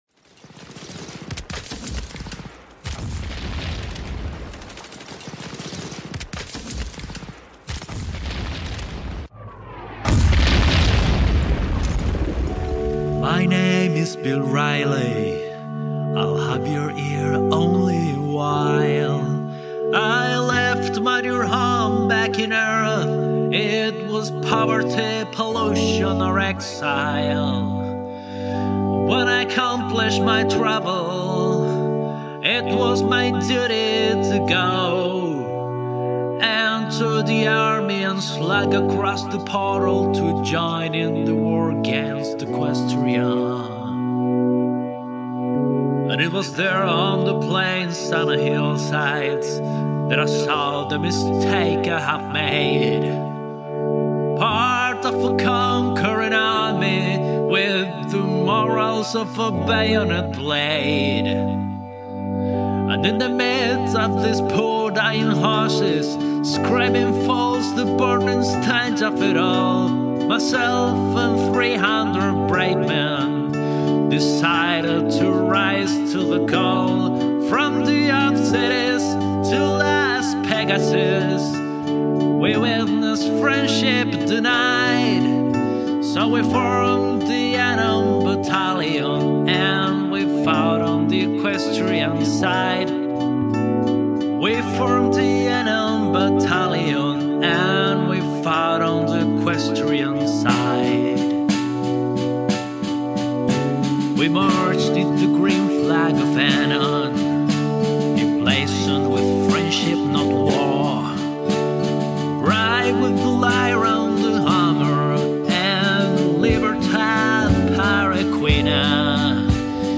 Irish folk song